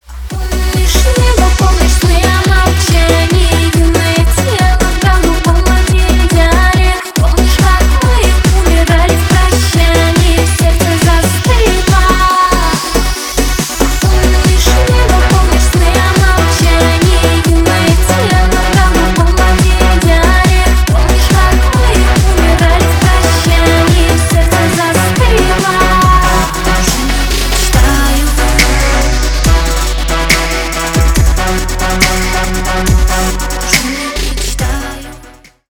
Ремикс
Танцевальные